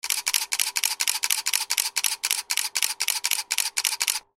Rapid-camera-shutter-sound-effect.mp3